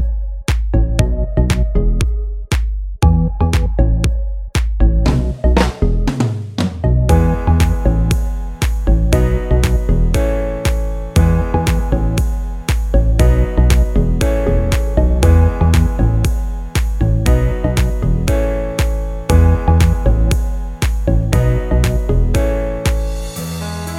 Minus Trumpet